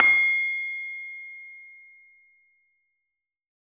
LAMEL C6  -L.wav